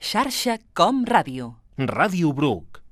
Indicatiu emissora